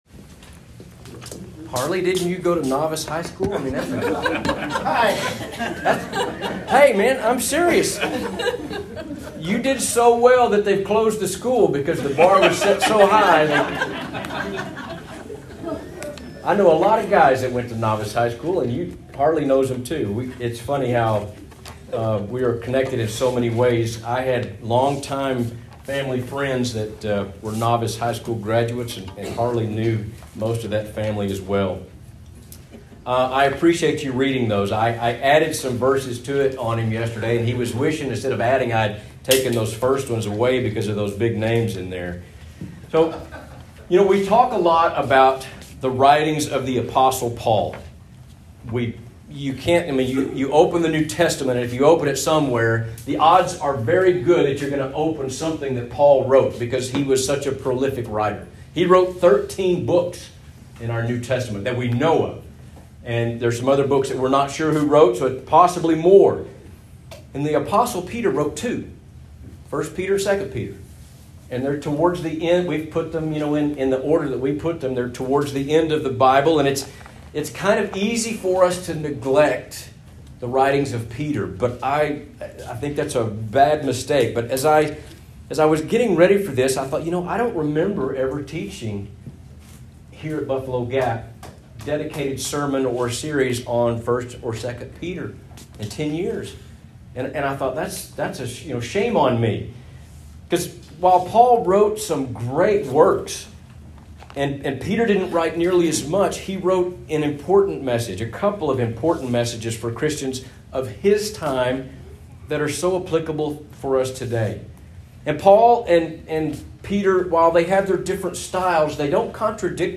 Sermons | Buffalo Gap Church of Christ